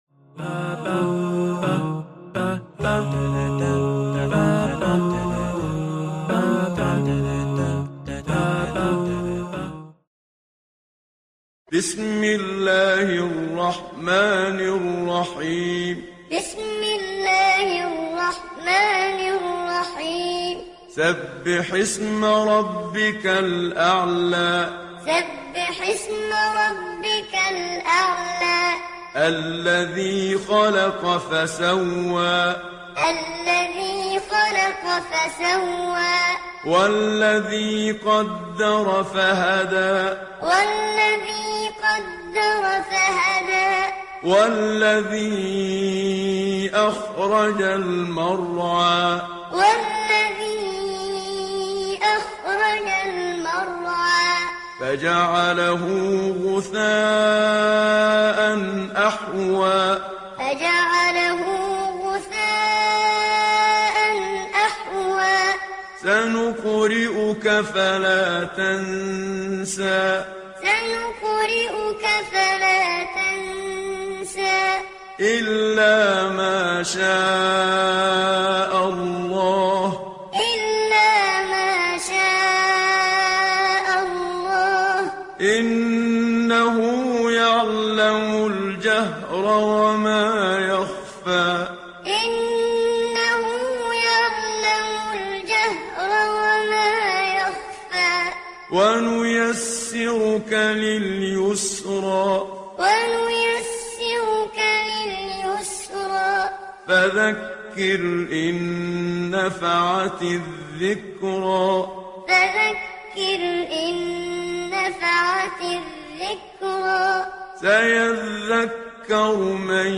087 - Al-A'la- Qur'an Time - Read Along.mp3